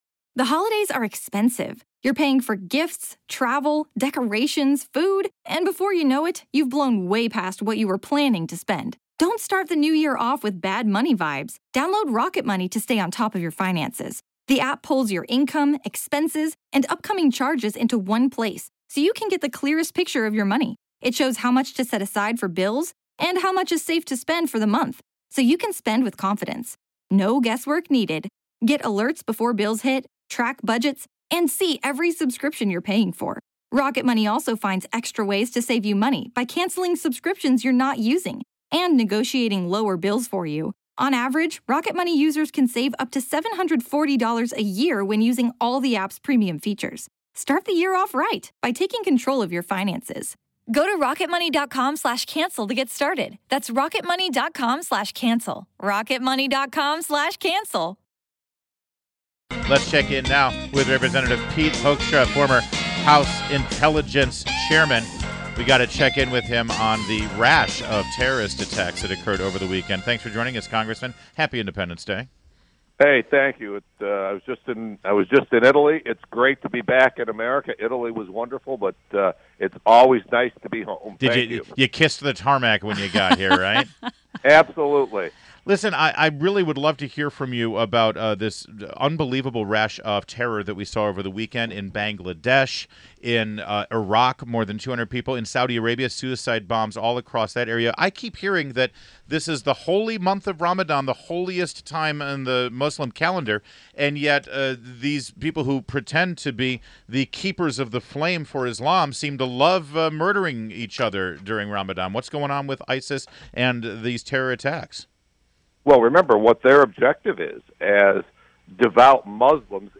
WMAL Interview Rep. Pete Hoekstra 07.05.16